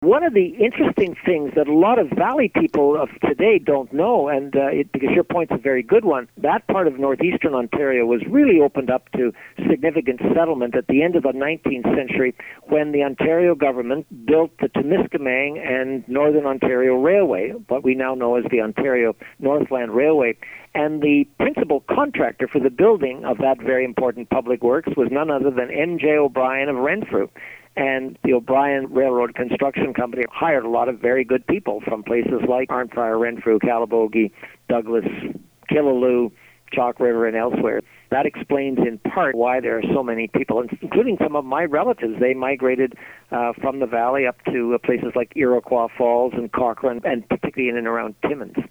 Sean Conway Order of Ontario interview